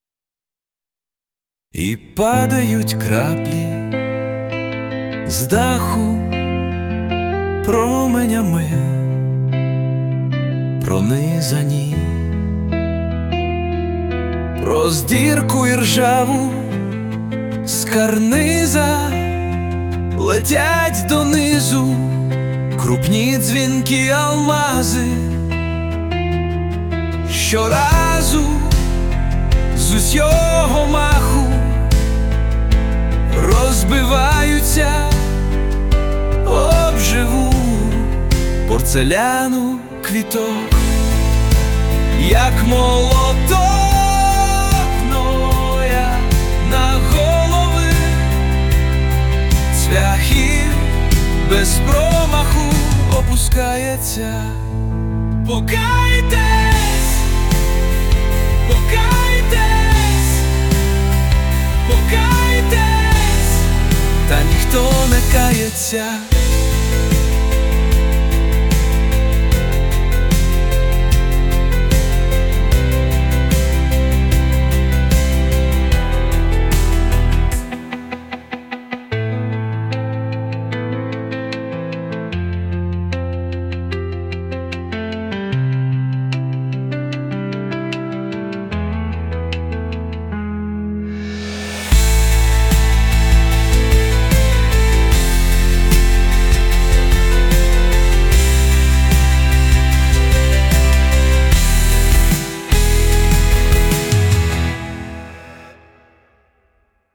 згенерував ШІ) - з відеорядом...
А виконання і музика, на мою думку, без душі, що не підсилює враження від вірша а понижує.